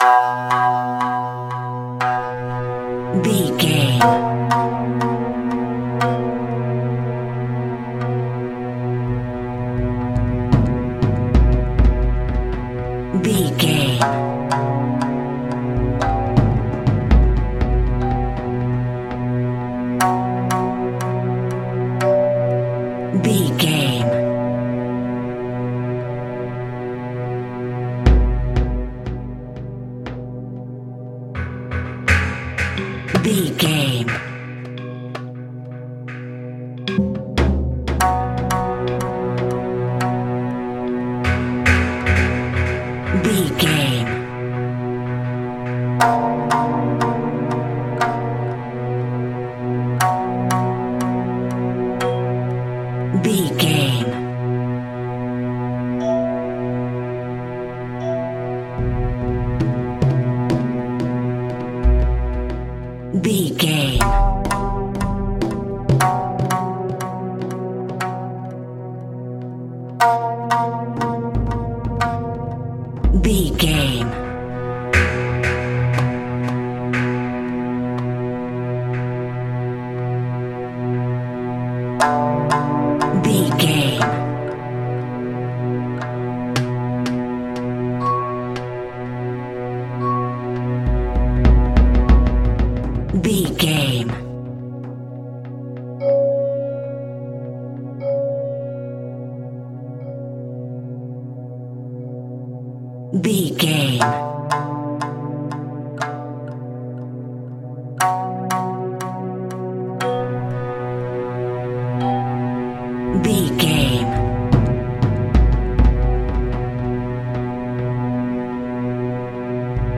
Aeolian/Minor
Slow
ominous
dark
suspense
haunting
eerie
strings
percussion
drums
synth
ambience
pads